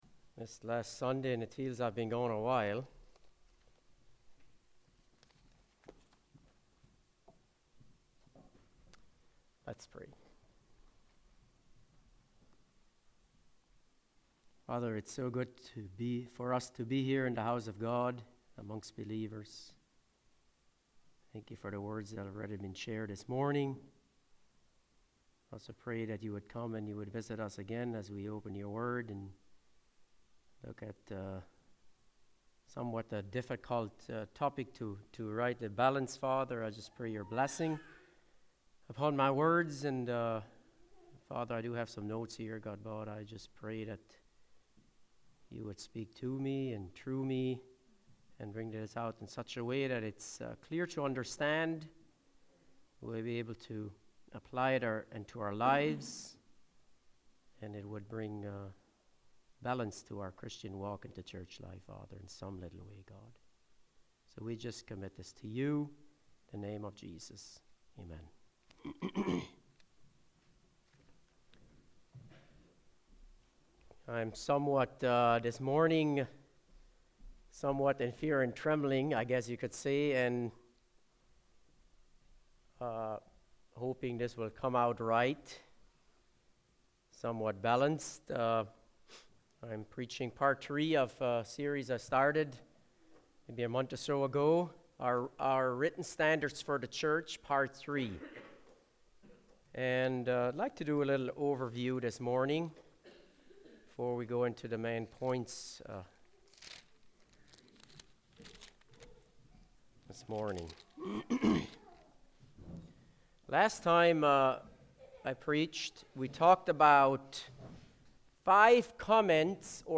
Service Type: Sunday Morning Speaker